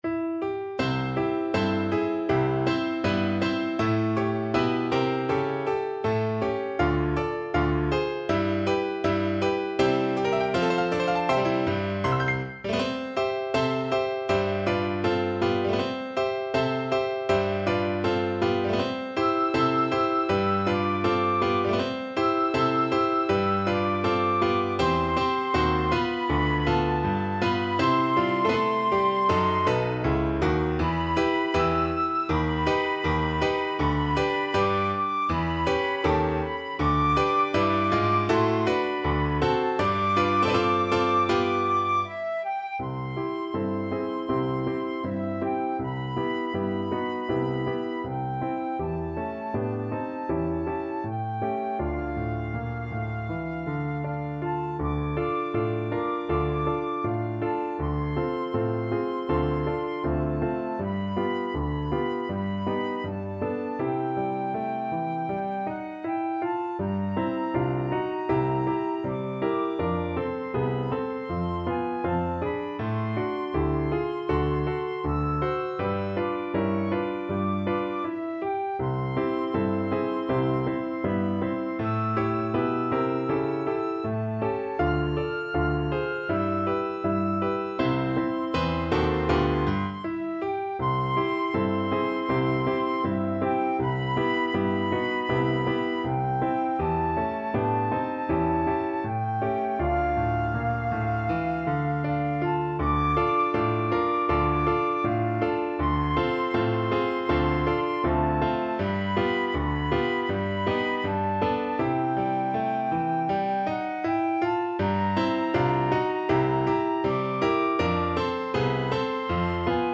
Moderato =80
2/2 (View more 2/2 Music)
Pop (View more Pop Flute Music)